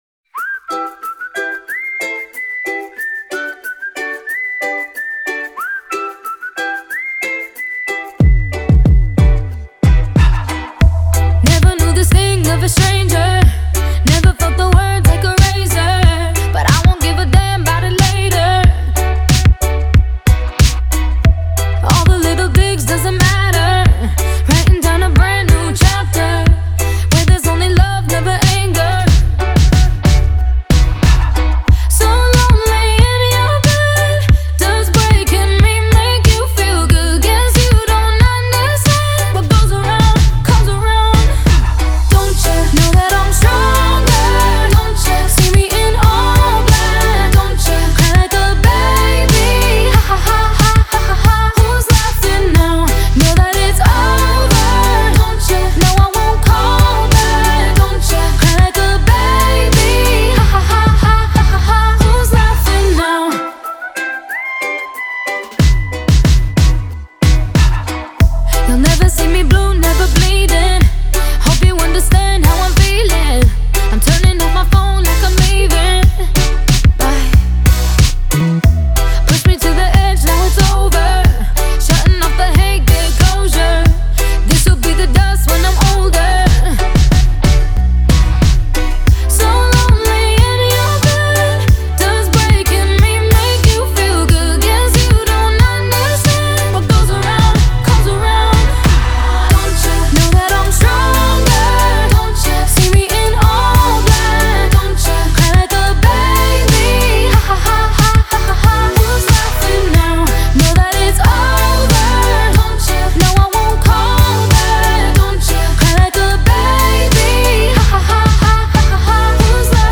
BPM92-92
Audio QualityPerfect (High Quality)
Pop song for StepMania, ITGmania, Project Outfox
Full Length Song (not arcade length cut)